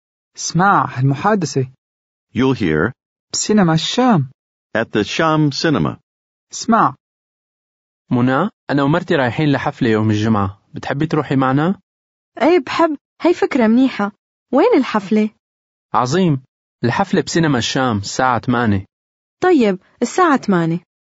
Аудио курс для самостоятельного изучения арабского языка.